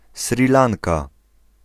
Ääntäminen
US : IPA : [ʃɹiː ˈlɑːŋ.kə]